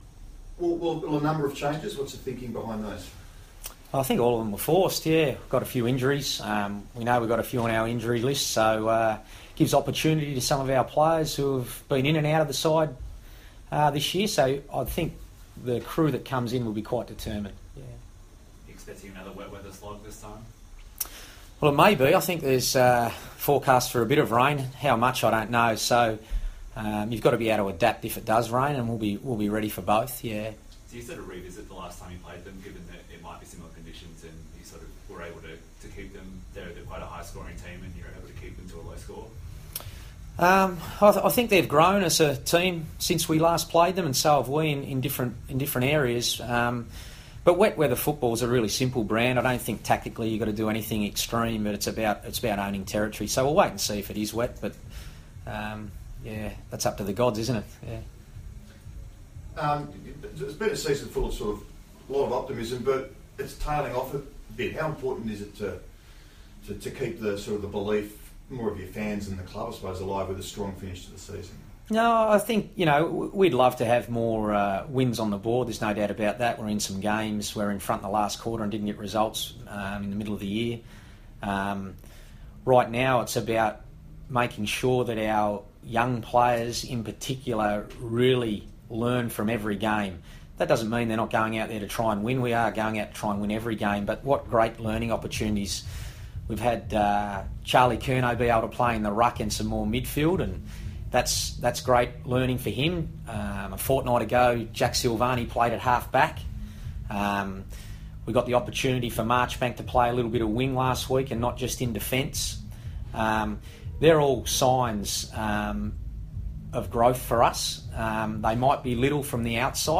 Brendon Bolton press conference - August 4
Carlton coach Brendon Bolton speaks to the media on the eve of the Blues' clash with Essendon.